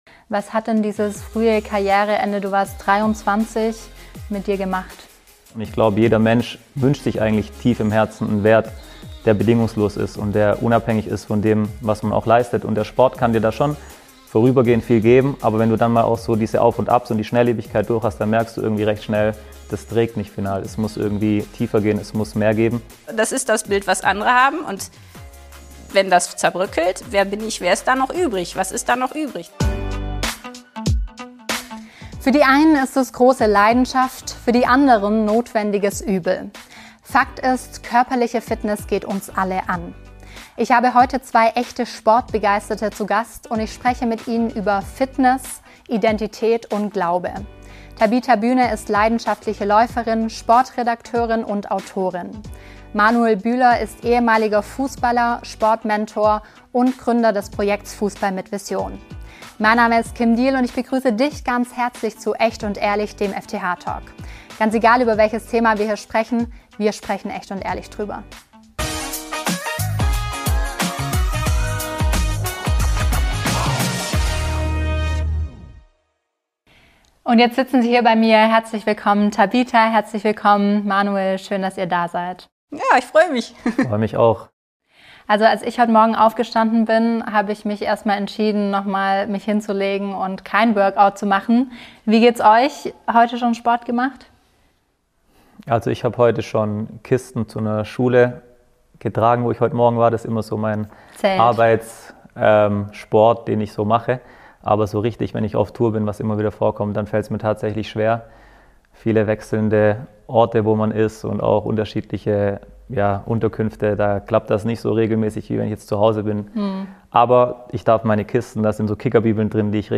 Darüber spricht Moderatorin